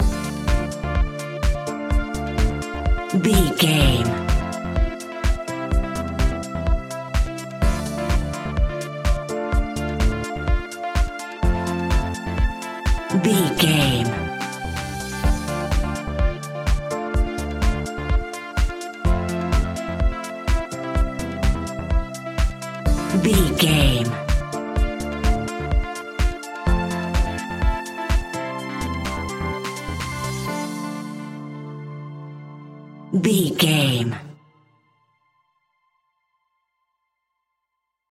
Aeolian/Minor
D
groovy
energetic
uplifting
hypnotic
drum machine
synthesiser
strings
funky house
upbeat
instrumentals